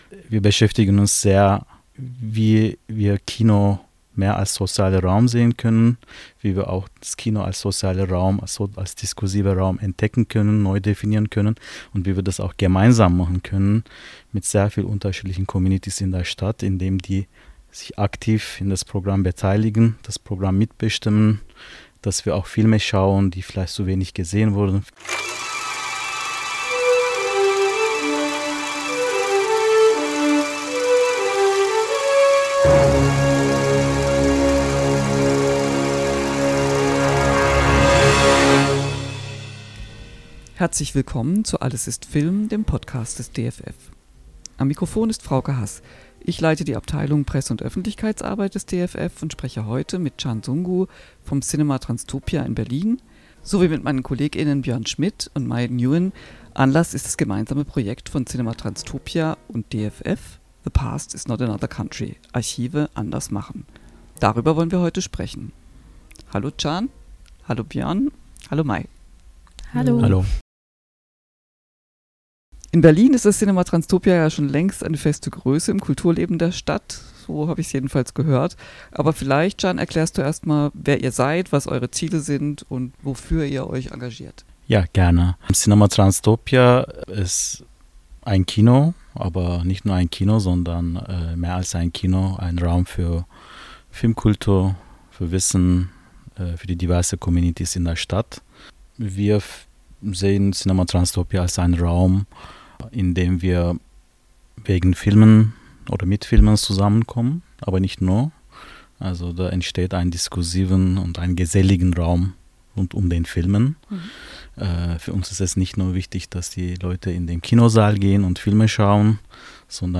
Im Podcastgespräch